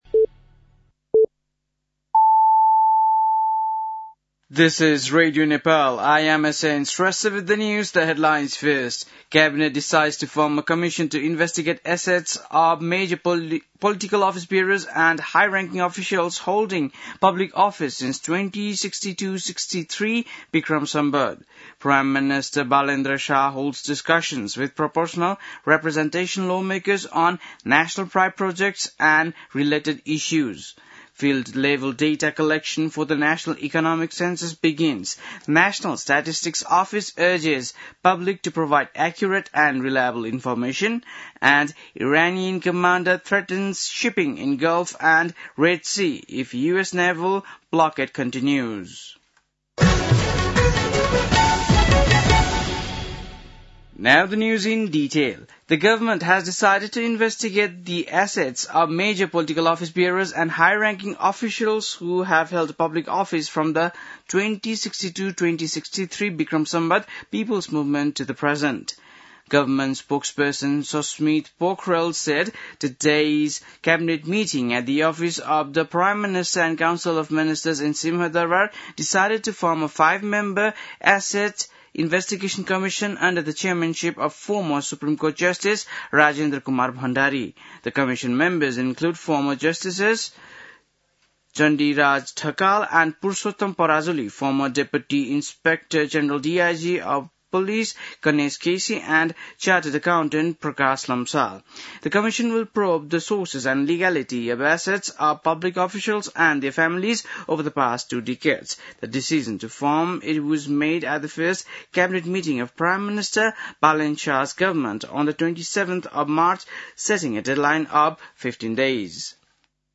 बेलुकी ८ बजेको अङ्ग्रेजी समाचार : ३ वैशाख , २०८३
8-pm-english-news-.mp3